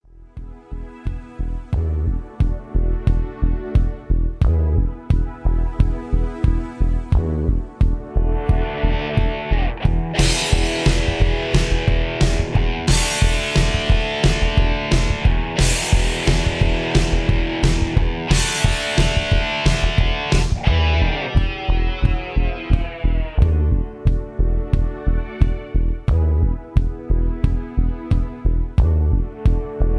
Key-E) Karaoke MP3 Backing Tracks
Just Plain & Simply "GREAT MUSIC" (No Lyrics).